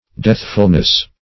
Deathfulness \Death"ful*ness\, n.
deathfulness.mp3